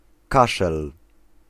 Ääntäminen
Ääntäminen : IPA : /kɑf/ US : IPA : [kɑf] RP : IPA : /kɒf/ Tuntematon aksentti: IPA : /kɔf/ Haettu sana löytyi näillä lähdekielillä: englanti Käännös Ääninäyte Substantiivit 1. kaszel {m} Verbit 2. kaszleć Määritelmät Verbit To push air from the lungs in a quick, noisy explosion.